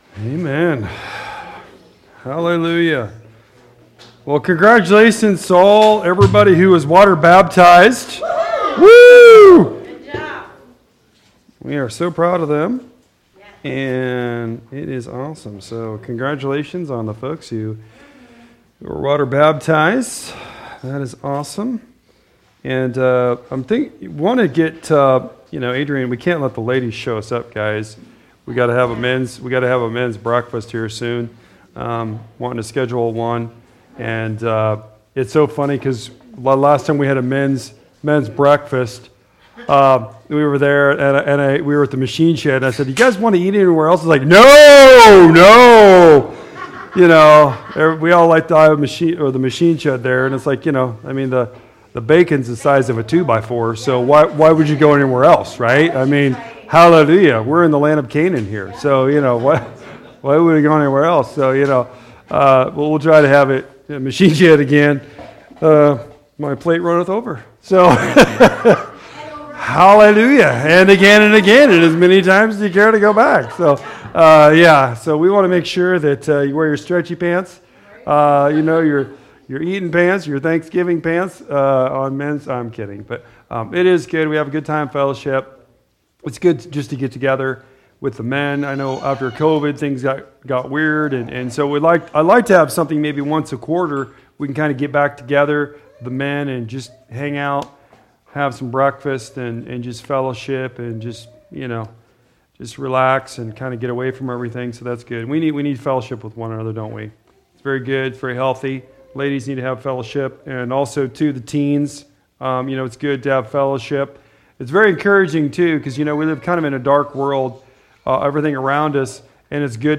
Resurrection Eve Service (Easter) 2025 Message